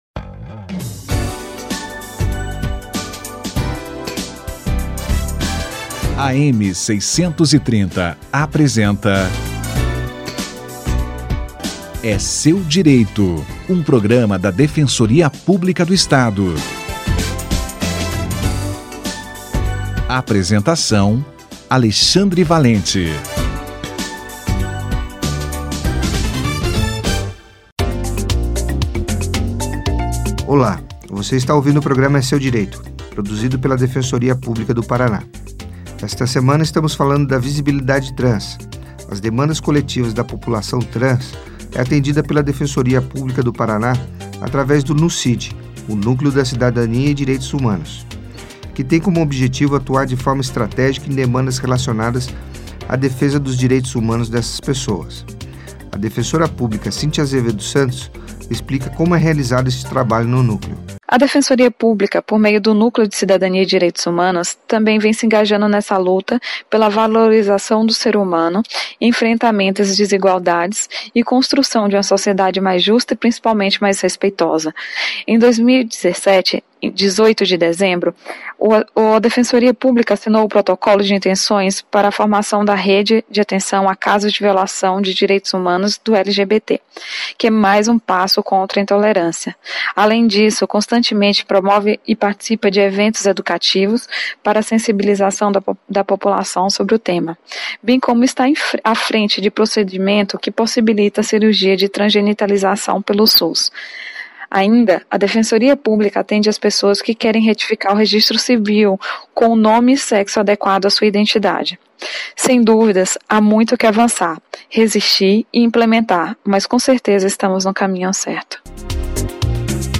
Atendimento da Defensoria para a população trans - entrevista